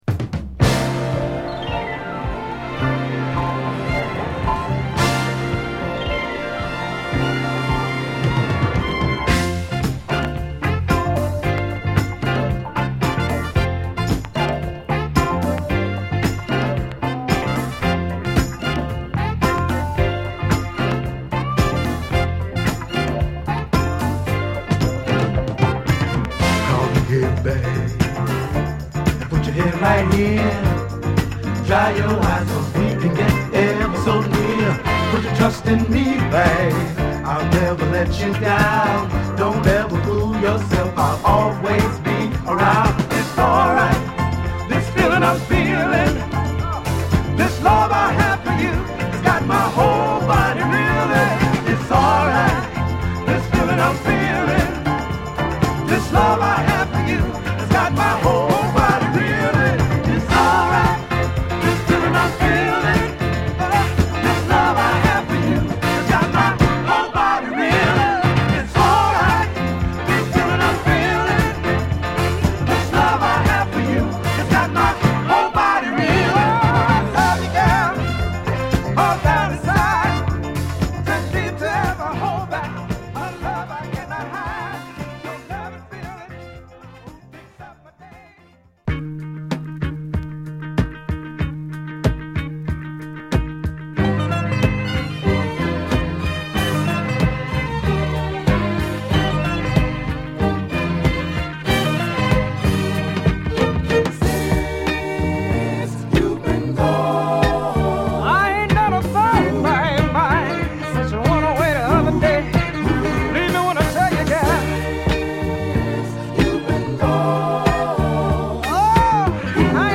シカゴ出身のヴォーカルグループ
壮大なアレンジが効いたイントロから軽快なリズムに乗ってソウルフルなヴォーカル＆コーラスをのせるグルーヴィーチューン！
爽快なストリングスも気持いいこれまたグルーヴィーなシカゴ・ソウル